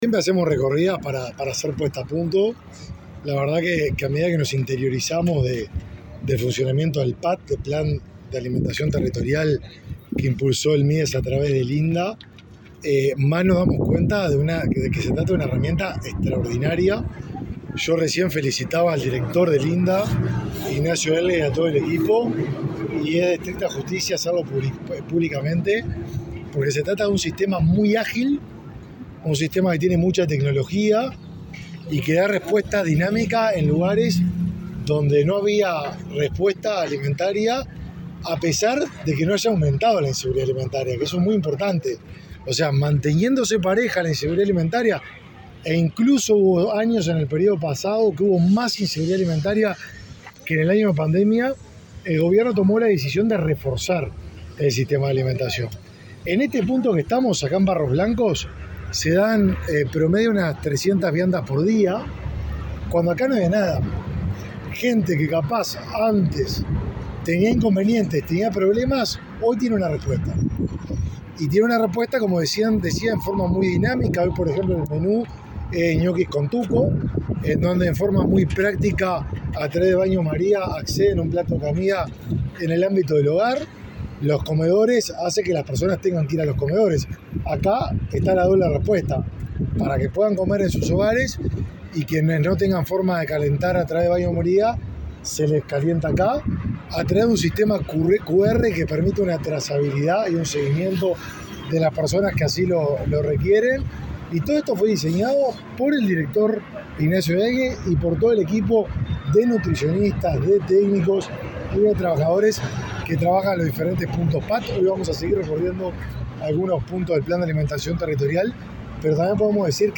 Declaraciones de autoridades del Mides
Declaraciones de autoridades del Mides 27/12/2023 Compartir Facebook X Copiar enlace WhatsApp LinkedIn El ministro de Desarrollo Social, Martín Lema, y el director del Instituto Nacional de Alimentación, Ignacio Elgue, dialogaron con la prensa, durante su visita al lugar donde se implementa el Plan de Alimentación Territorial, en el kilómetro 24,7 de la ruta n°. 8.